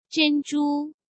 zhēn zhū